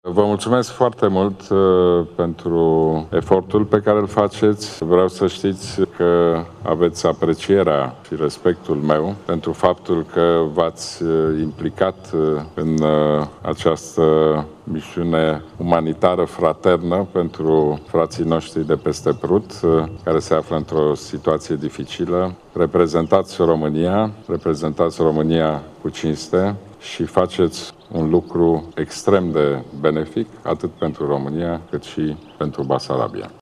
Pentru a marca momentul, o ceremonie a fost organizată la sediul Inspectoratului pentru Situaţii de Urgenţă din Iaşi, la care a luat parte şi premierul Ludovic Orban.